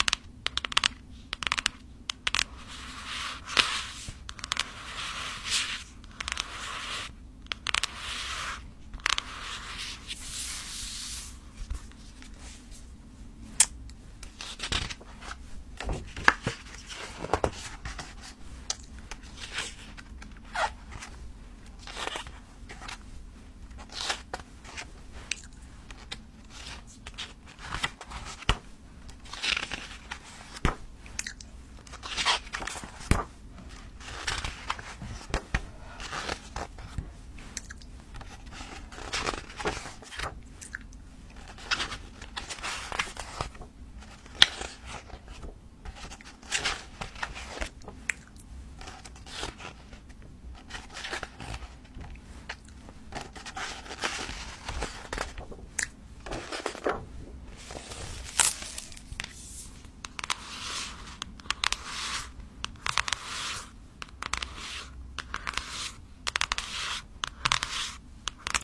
book sounds trigger assortment +